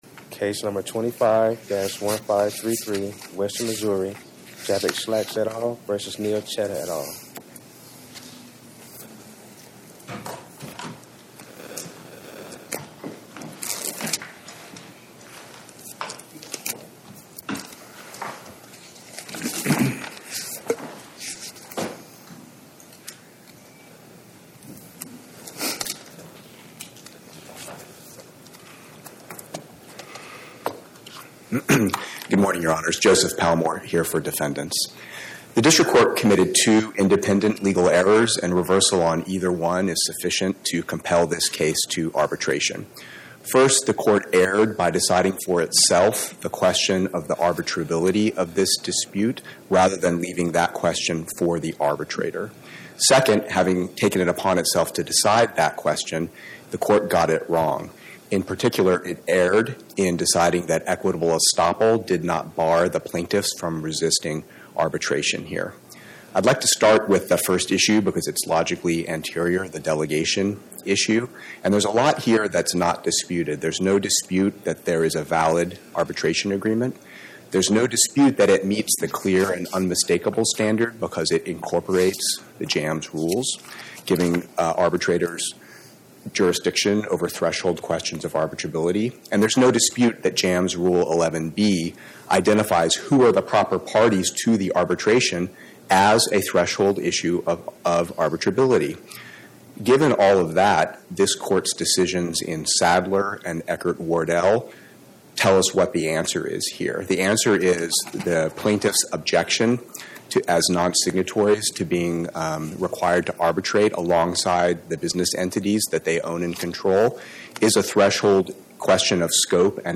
Oral argument argued before the Eighth Circuit U.S. Court of Appeals on or about 12/18/2025